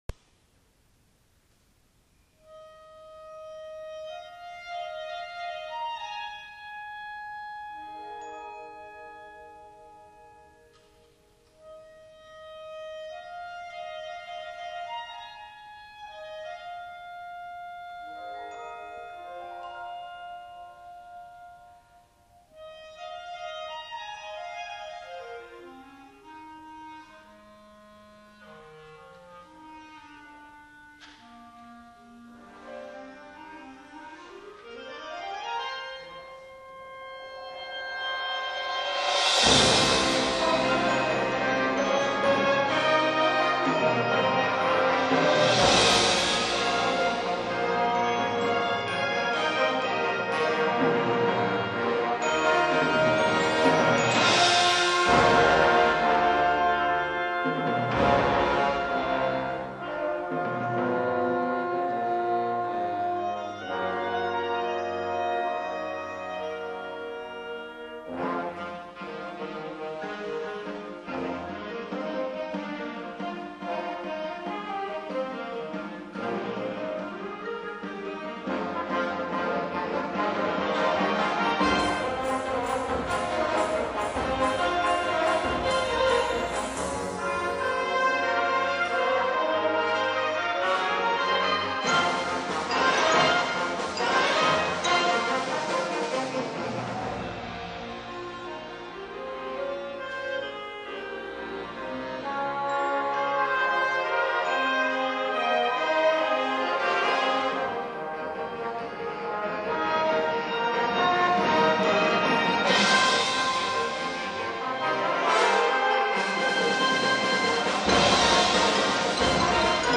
吹奏楽部
吹奏楽部が全日本吹奏楽コンクール四国支部大会で銅賞を受賞しました。